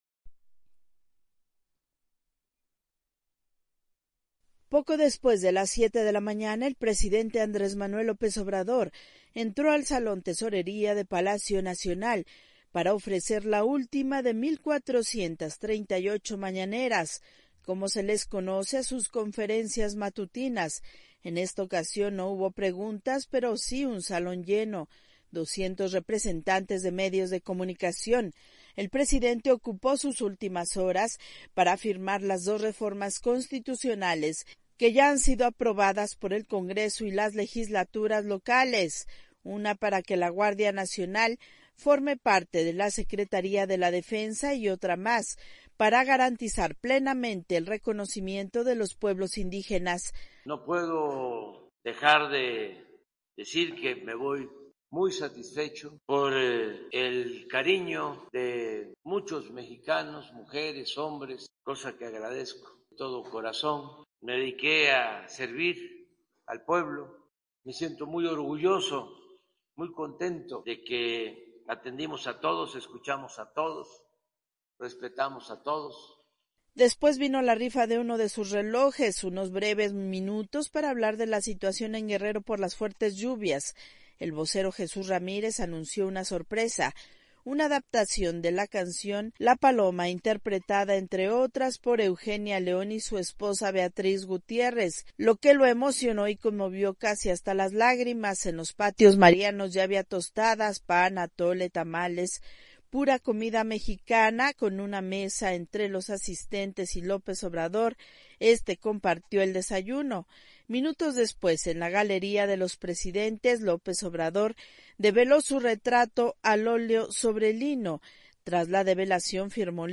AudioNoticias
desde Ciudad de México